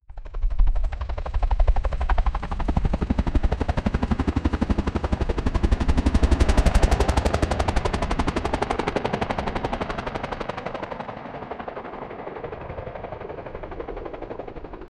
helicopter-flying-ink6hkky.wav